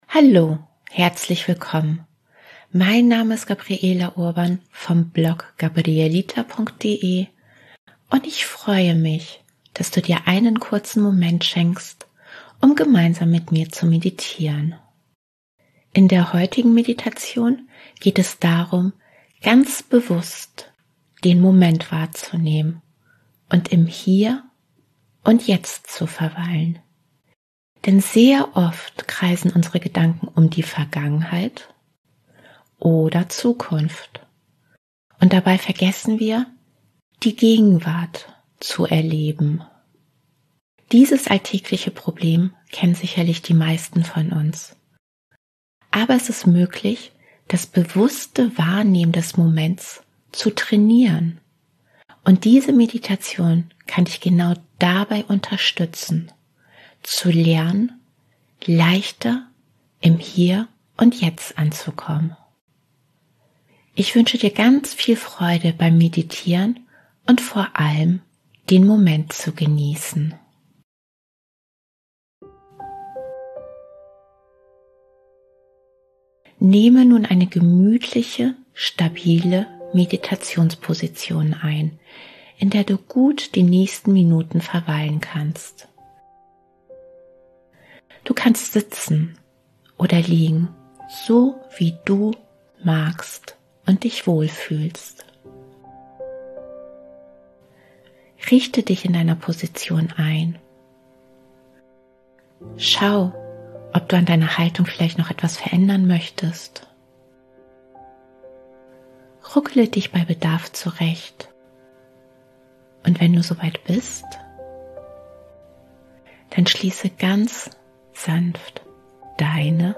In der heutigen geführten Meditation geht es darum, den Moment bewusst wahrzunehmen – und mithilfe einer einfachen, bewussten Technik zu lernen, im Hier und Jetzt zu verweilen. Denn sehr oft kreisen unsere Gedanken um die Vergangenheit oder Zukunft.